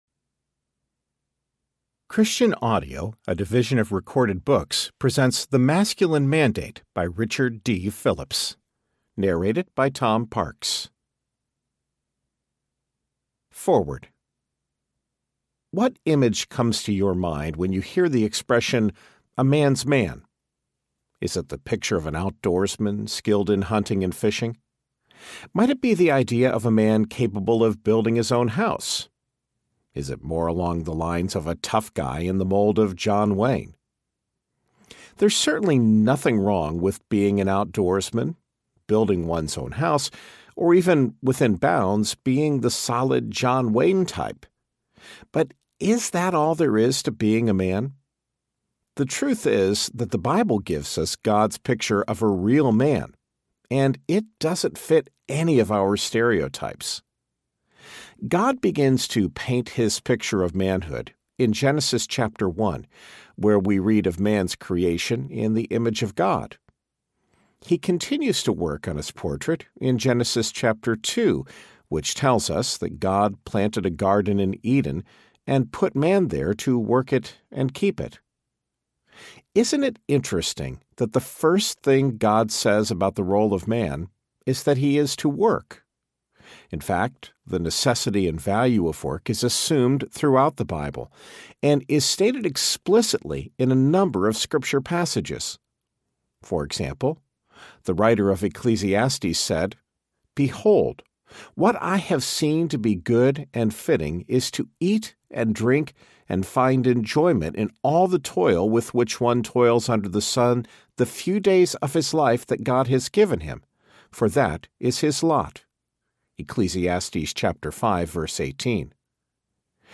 Masculine Mandate Audiobook
6.31 Hrs. – Unabridged